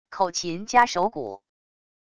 口琴加手鼓wav音频